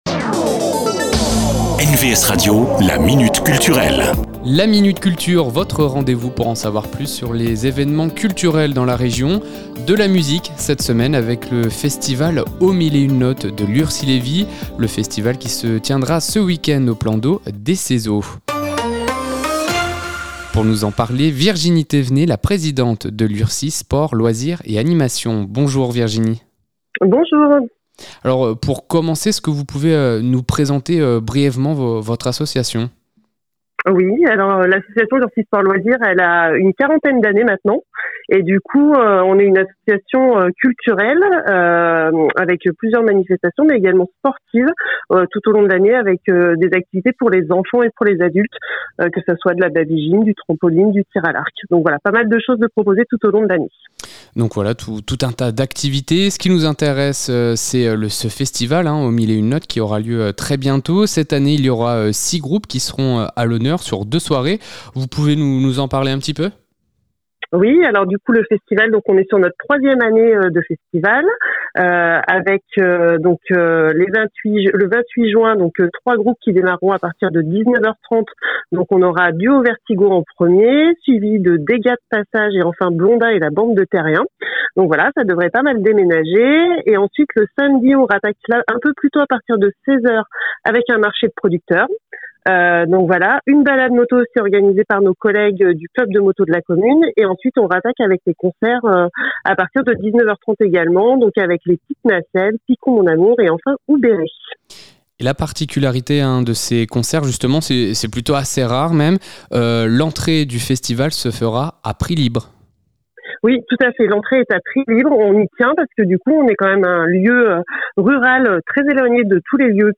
La Minute Culture, rencontre avec les acteurs culturels de la région.